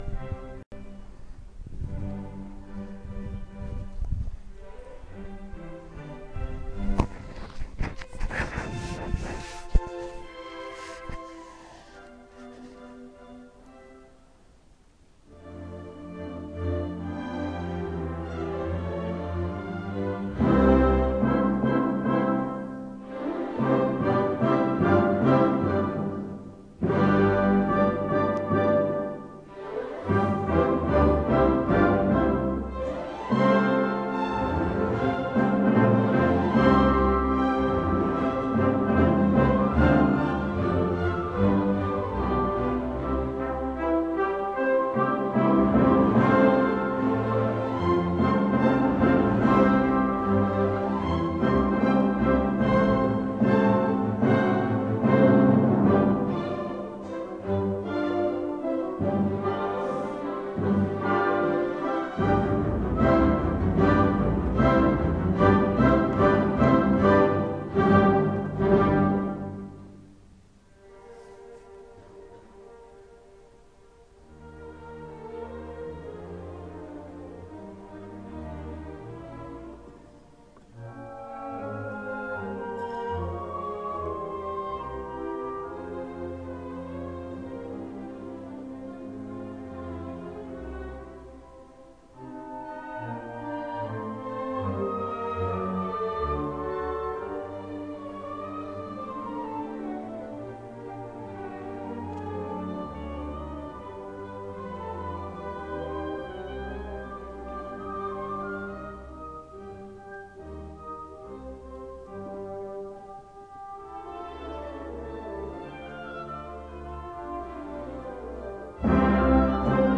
Beethoven - Concerto No. 1 for Piano & Orchestra in C Major, op. 15 (I. Allegro con brio)